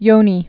(yōnē)